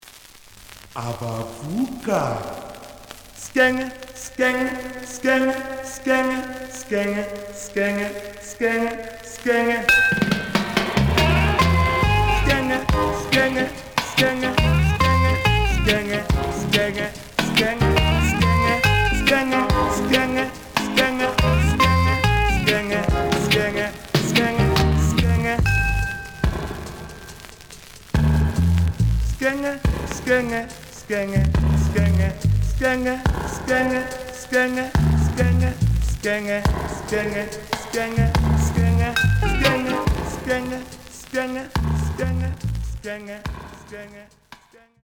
試聴は実際のレコードから録音しています。
●Genre: Reggae
●Record Grading: VG (両面のラベルにダメージ。両面ノイジー。)